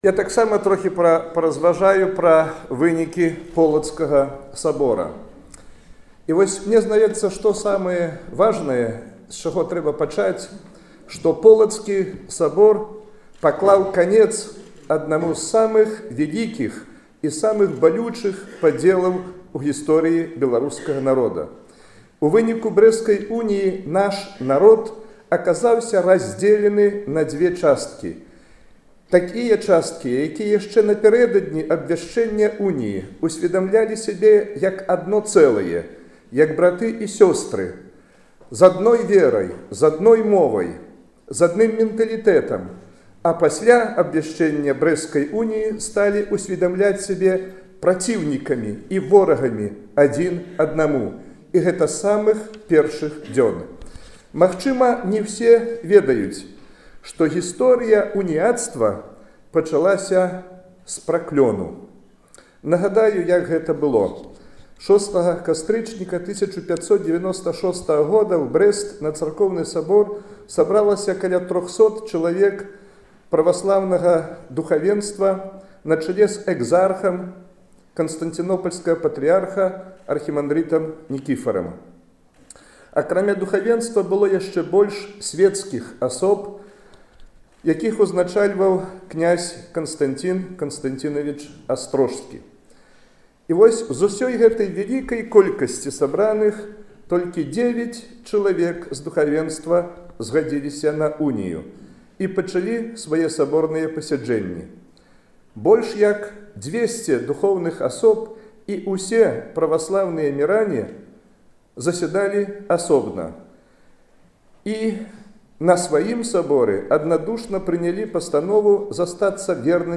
Прапануем вам аўдыё і тэкставую версію яго прамовы.